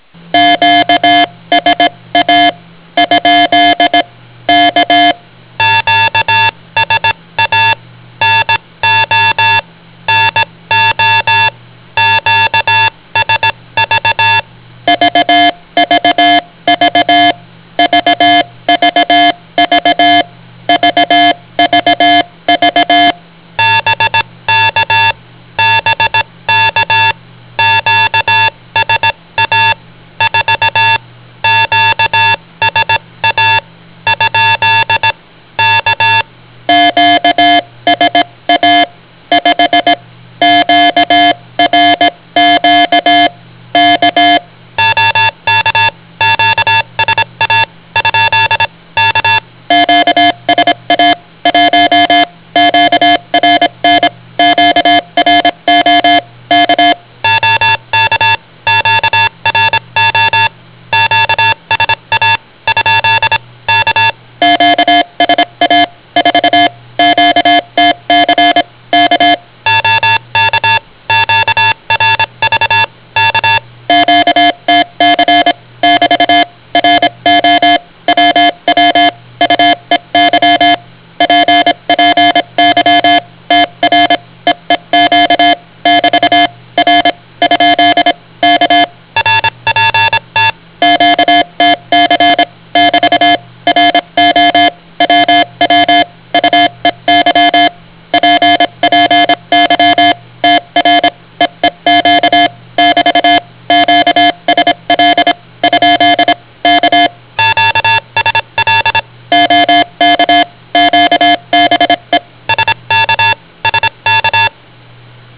Funkspruch abgefangen.